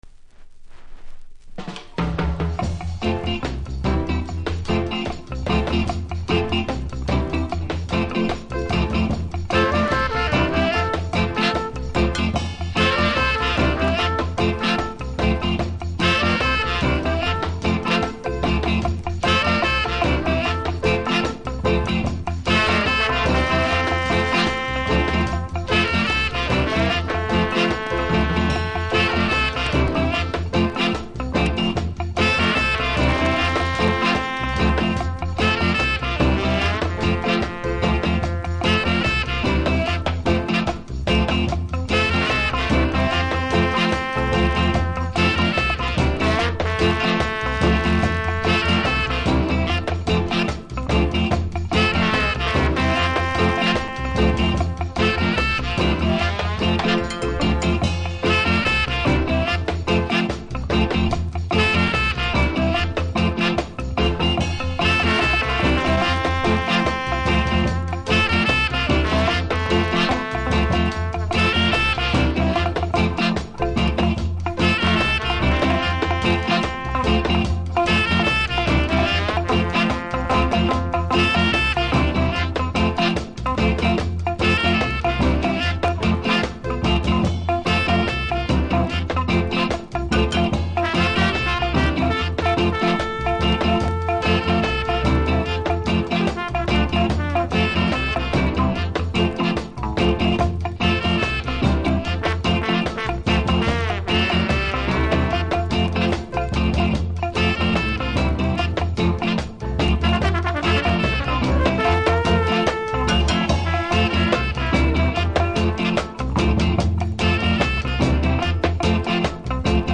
B1だけプラス起因の凹があり終盤にノイズありますので試聴で確認下さい。